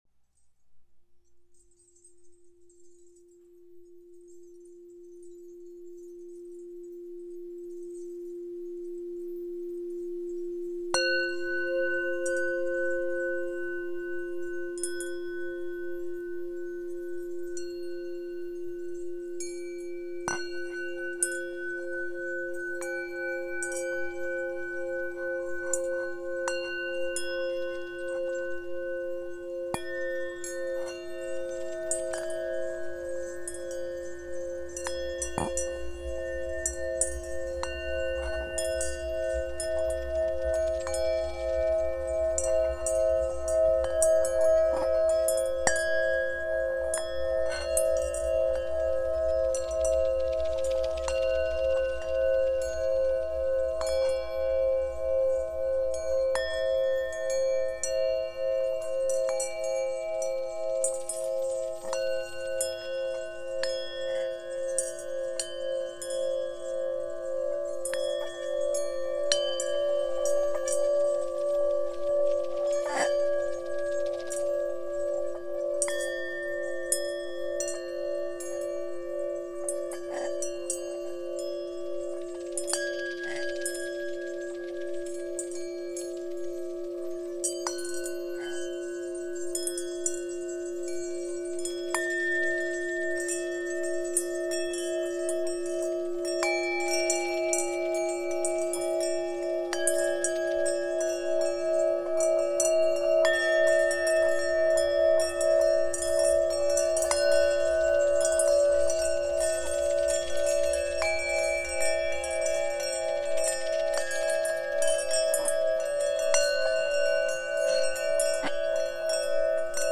RadiantHeartSelfLoveMeditation.m4a